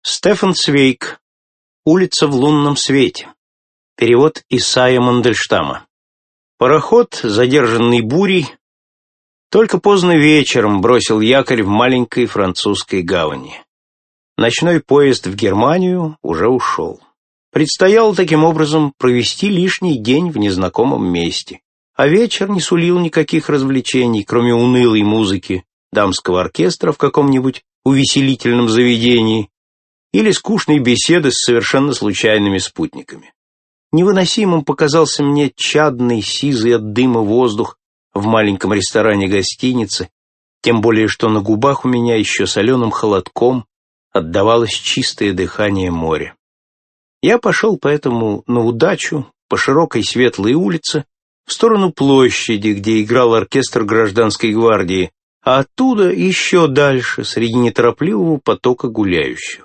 Аудиокнига Фантастическая ночь. Сборник новелл | Библиотека аудиокниг
Сборник новелл Автор Стефан Цвейг Читает аудиокнигу Александр Клюквин.